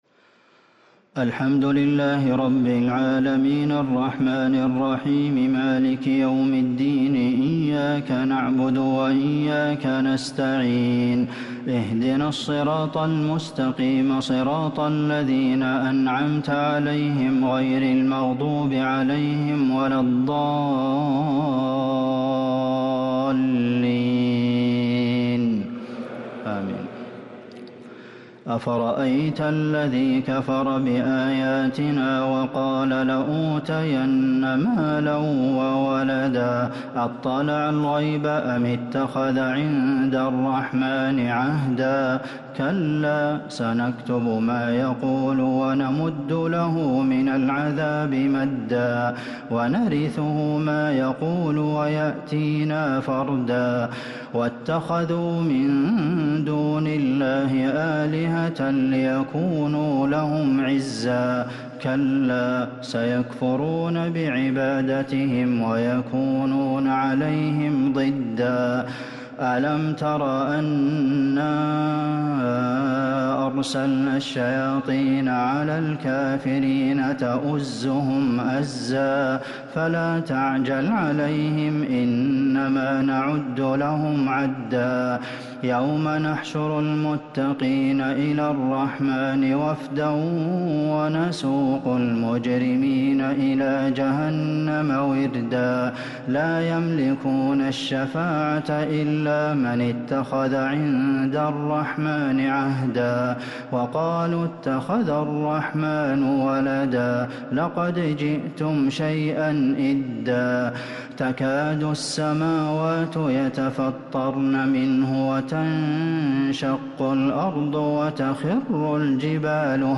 تراويح ليلة 21 رمضان 1444هـ من سورتي مريم (77-98) و طه (1-76) | Taraweeh 21 th night Ramadan 1444H Surah Maryam and Taa-Haa > تراويح الحرم النبوي عام 1444 🕌 > التراويح - تلاوات الحرمين